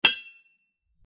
Metal Clank 11
Metal_clank_11.mp3